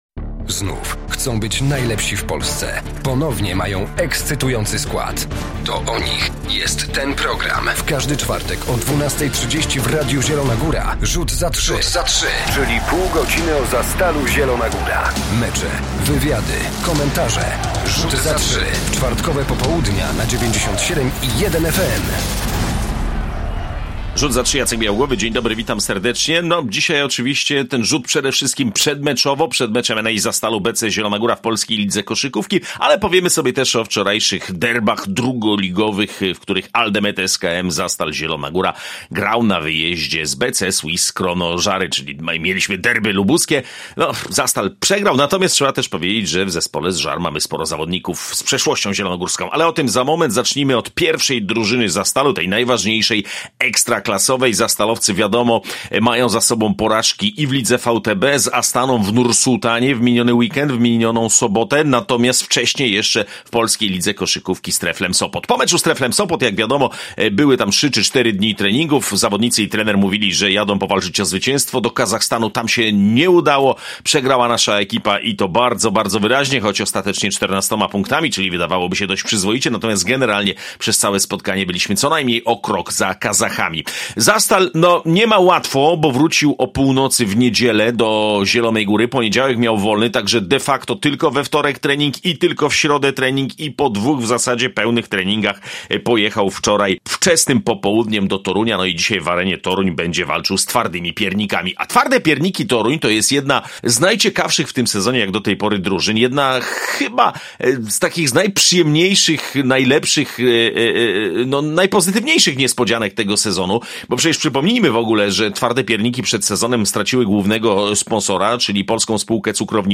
Zapraszamy na cotygodniowy magazyn koszykarski Rzut za trzy.
Poza tym jesteśmy w Żarach – mówimy o wczorajszym spotkaniu w II lidze – w derbach BC SwissKrono żary wygrało z Aldemedem SKM Zastalem Zielona Góra. I mamy rozmowy z bohaterami meczu.